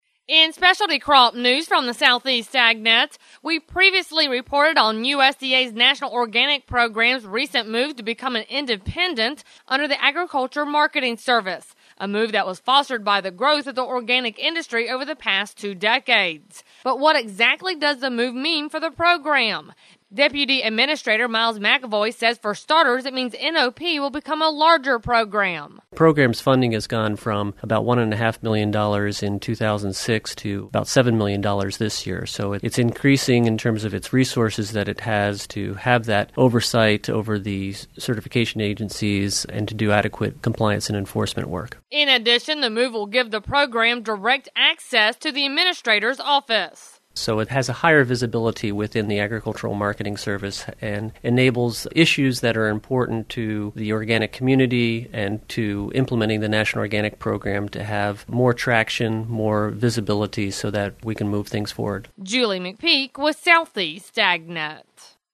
USDA’s National Organic Program has recently become independent and Deputy Administrator Miles McElvoy explains what this means for the program.